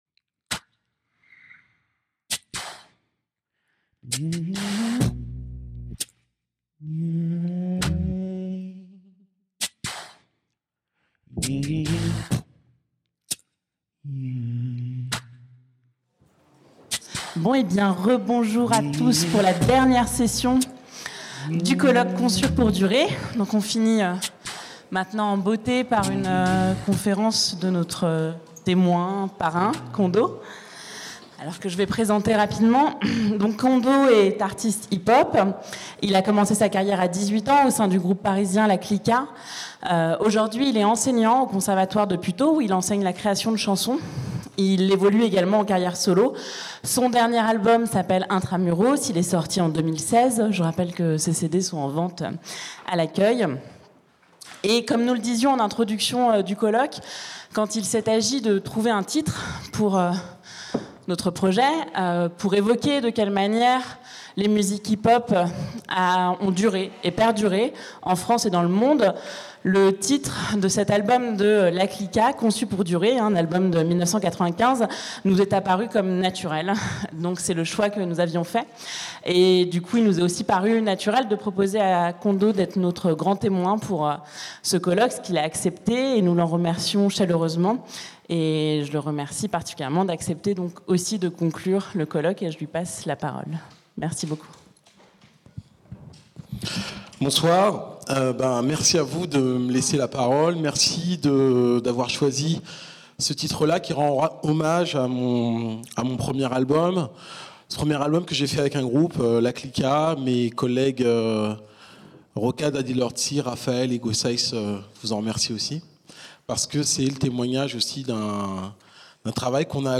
Conférence de conclusion d'un colloque sur le hip-hop | Canal U